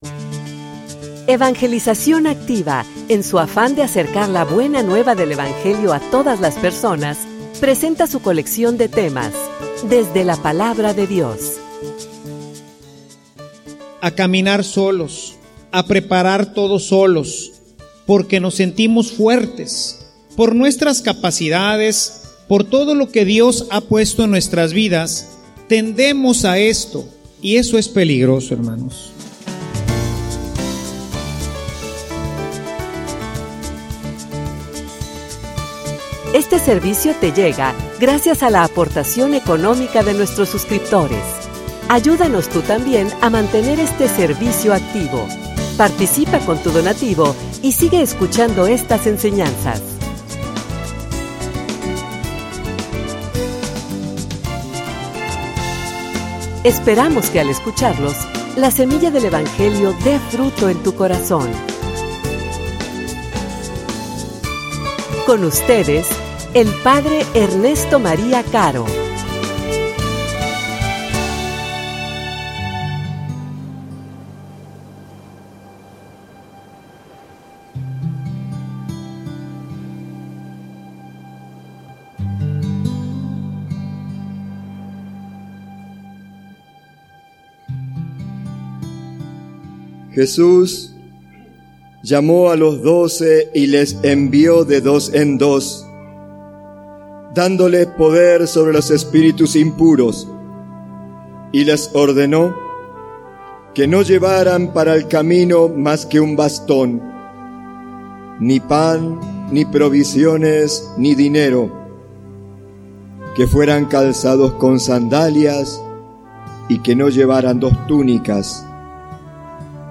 homilia_Enviados_como_profetas.mp3